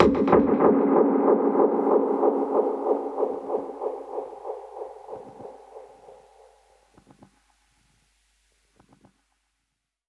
Index of /musicradar/dub-percussion-samples/95bpm
DPFX_PercHit_A_95-05.wav